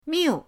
miu4.mp3